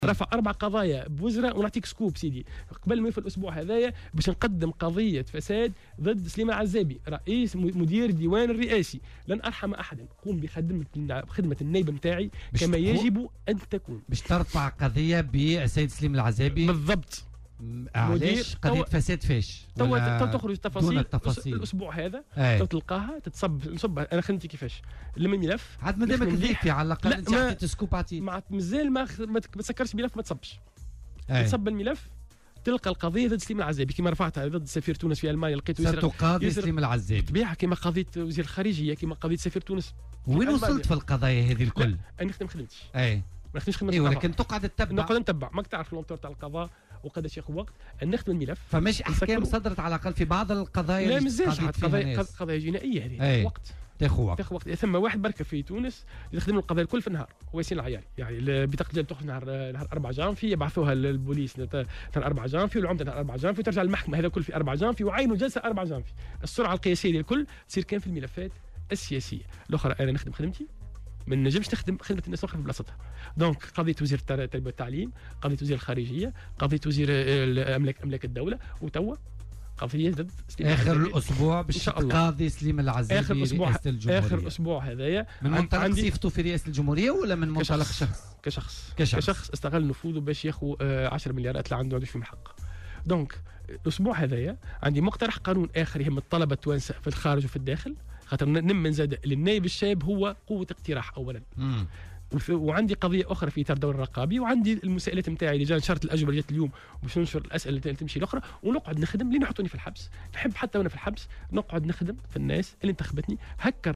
قال النائب بمجلس الشعب، ياسين العياري، ضيف برنامج "بوليتيكا" اليوم الخميس، أنه سيتقدّم بقضية ضدّ مدير الديوان الرئاسي، سليم العزابي.